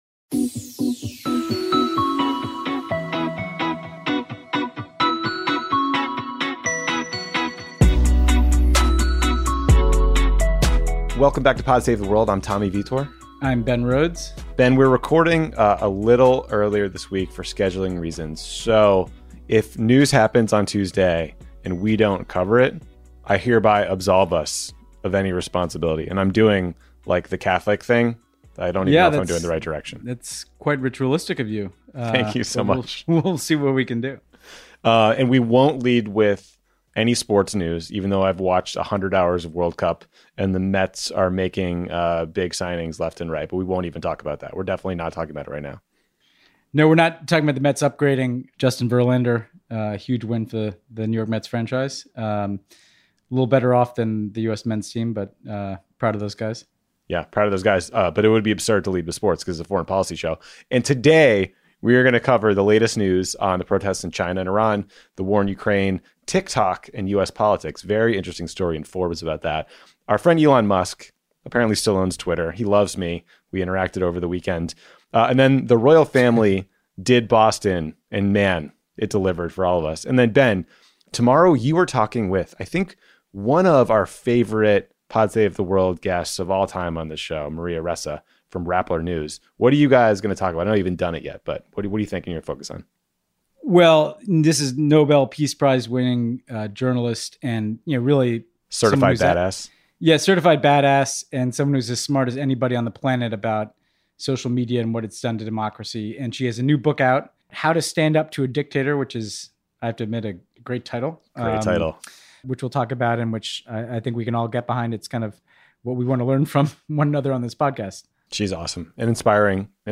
Tommy and Ben talk about the ongoing protests in China, Ukrainian strikes into Russia, speculation over Putin’s health, Iran sending mixed messages on the morality police, and Bostonians greeting the royals as warmly as expected. Then, Ben interviews Maria Ressa about the evolution of disinformation spread and how to combat it in the face of autocratic regimes.